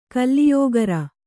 ♪ kalliyōgara